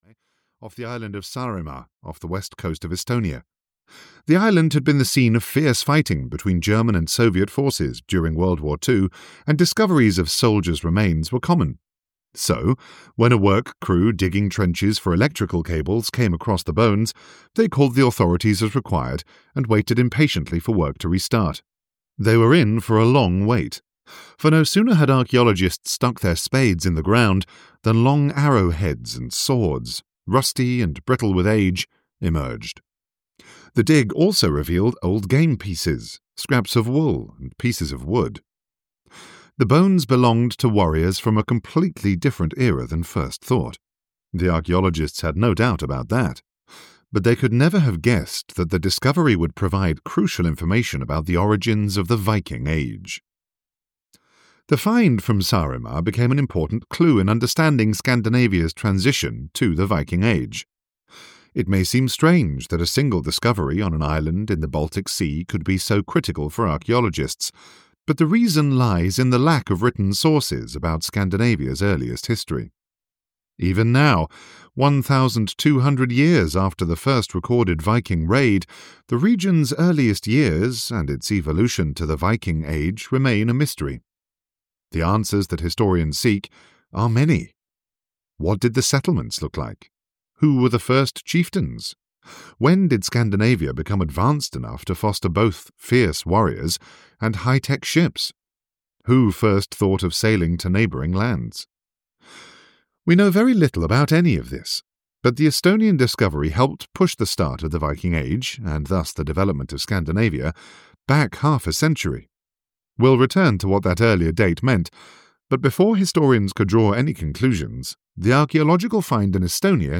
Vikings – Final Secrets Revealed (EN) audiokniha
Ukázka z knihy